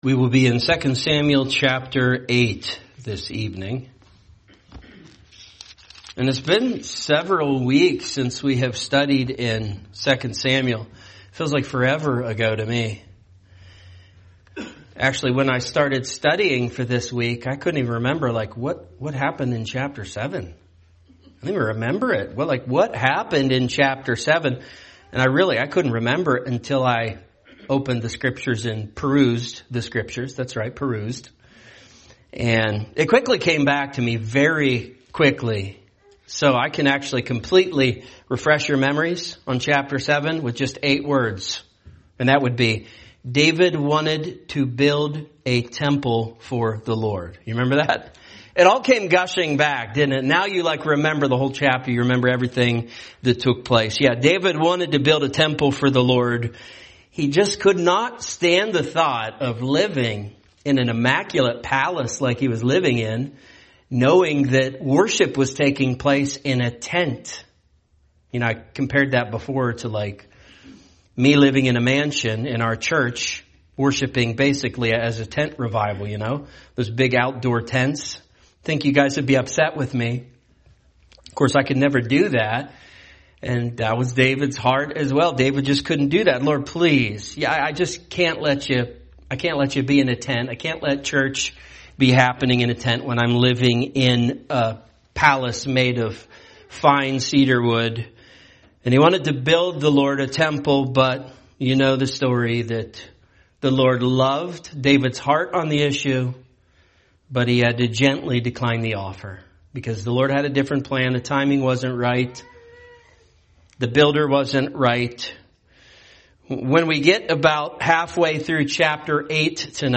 A message from the topics "The Book of 2 Samuel."